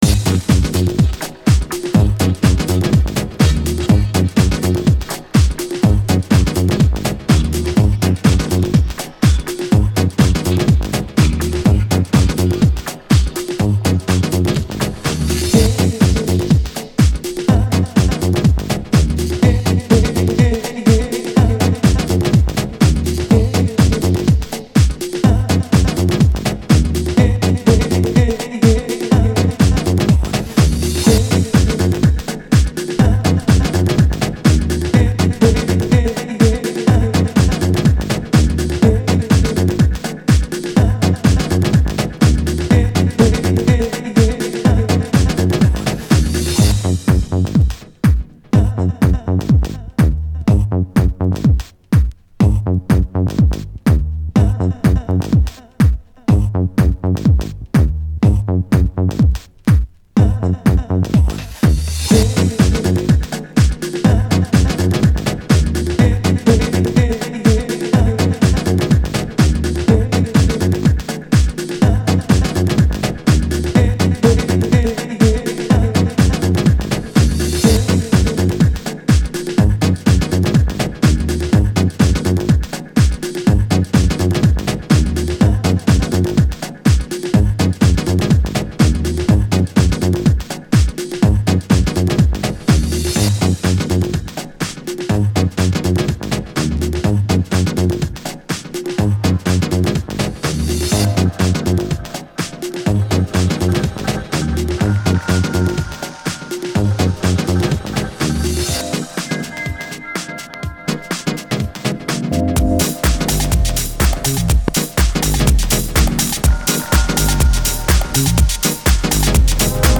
Muzyka/Music: House/Disco/Minimal Techno/Funk
Exclusive DJ mix session.